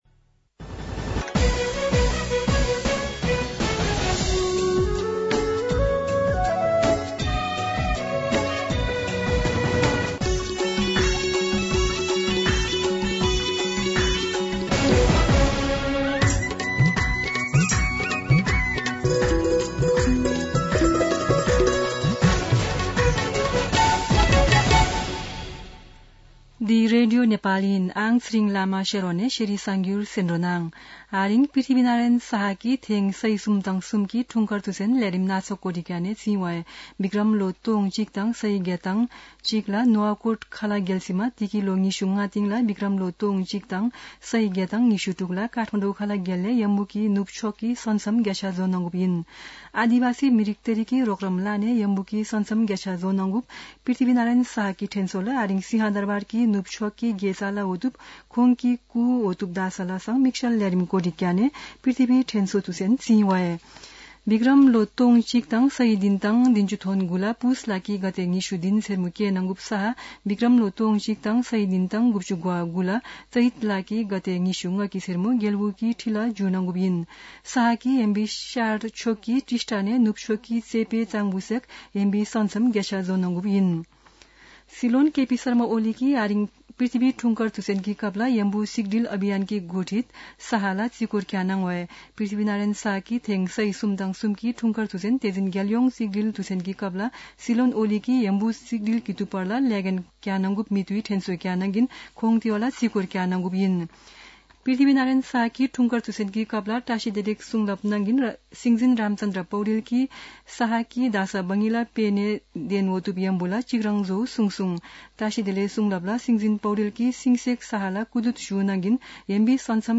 शेर्पा भाषाको समाचार : २८ पुष , २०८१
Sherpa-News-9-27.mp3